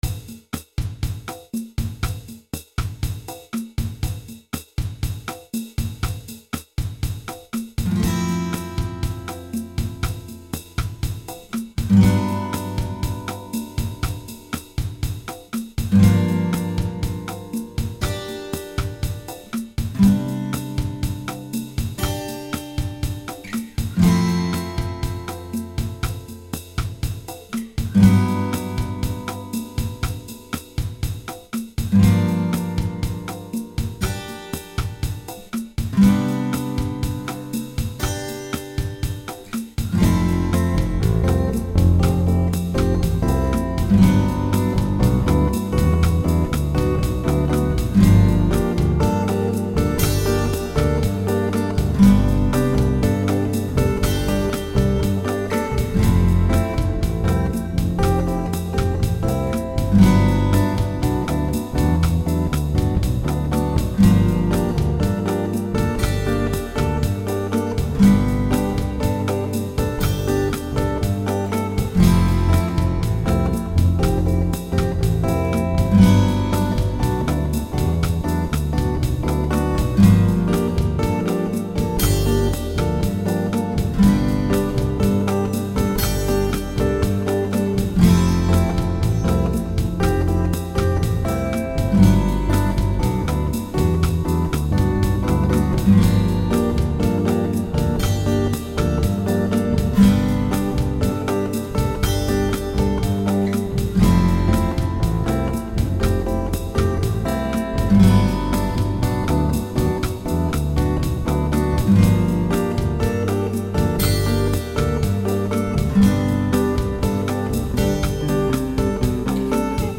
preliminary live recordings.